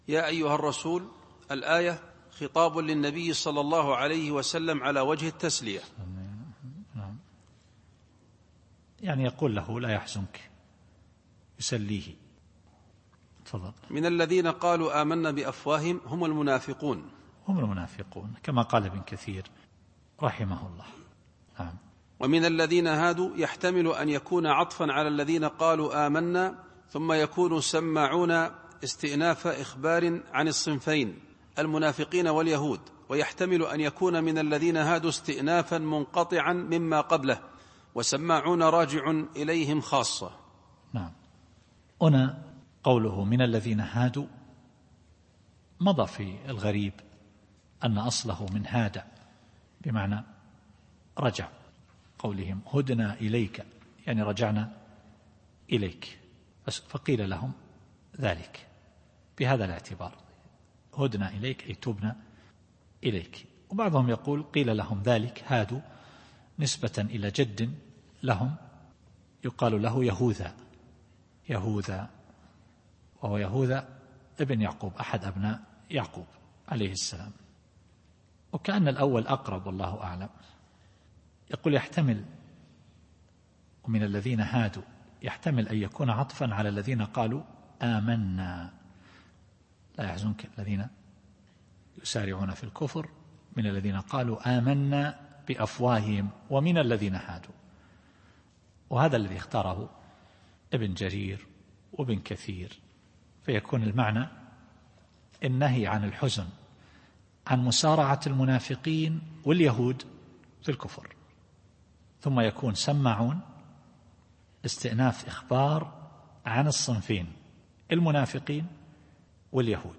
التفسير الصوتي [المائدة / 41]